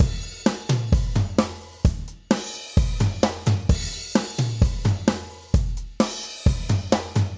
Prog Rock 130 4 Bars 44
描述：一个略带古怪的驱动型摇滚乐节拍。
Tag: 130 bpm Rock Loops Drum Loops 1.24 MB wav Key : Unknown